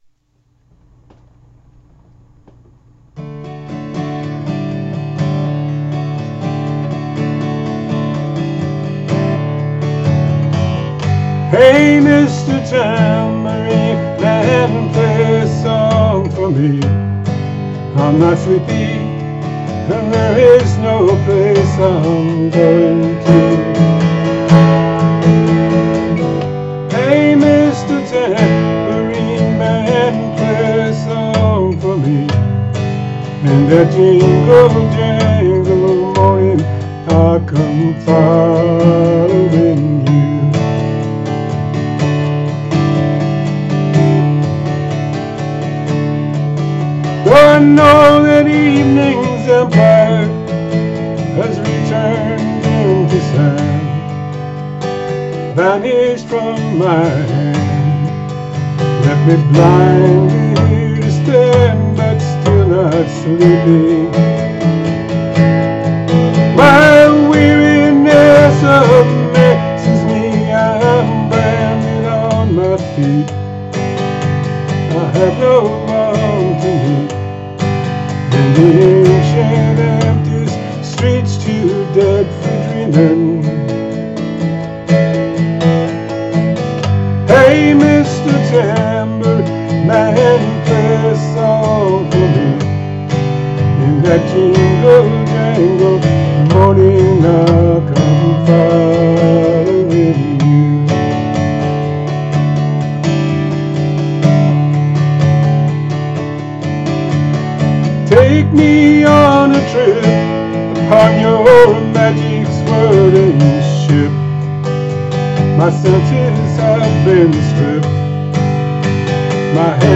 The melody is haunting and mesmerizing.